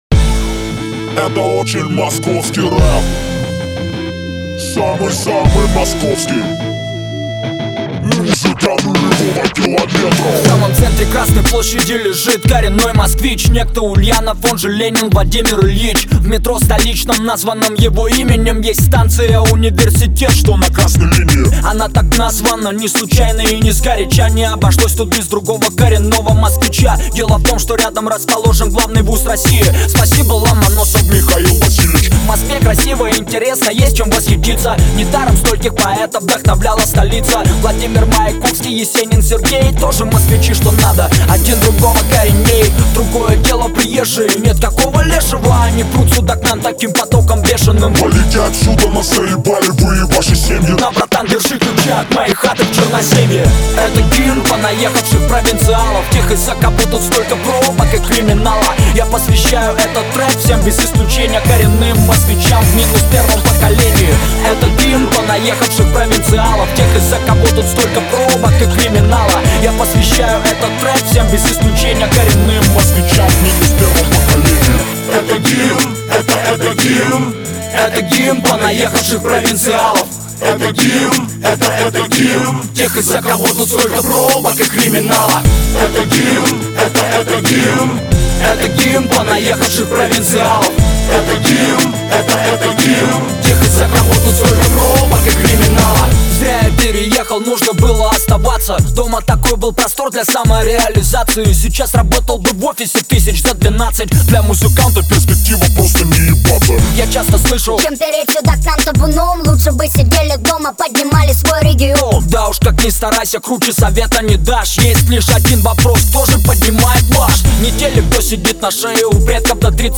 Категория: Русский рэп